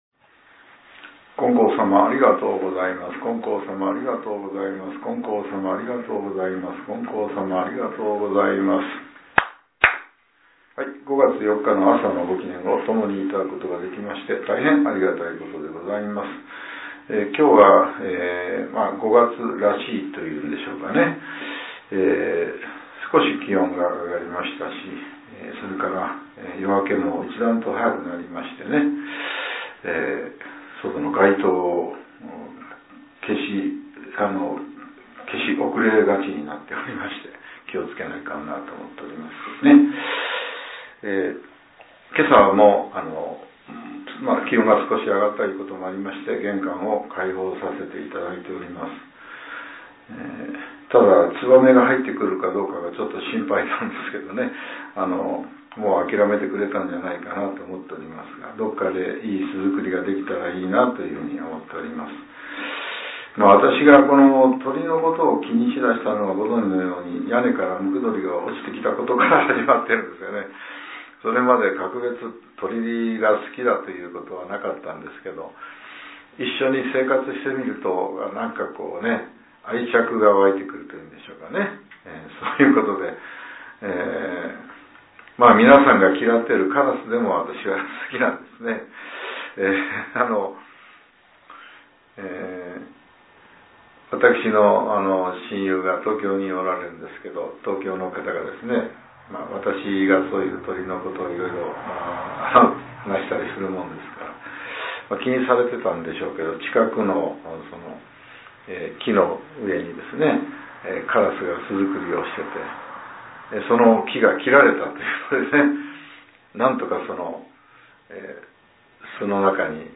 令和７年５月４日（朝）のお話が、音声ブログとして更新されています。